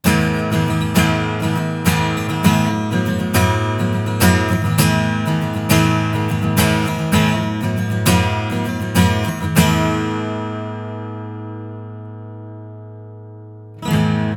MXL V67Gは単一指向性のコンデンサーマイクで、
実際の録り音
アコースティック。ギター
EQはローカットのみしています。
V67-アコギ.wav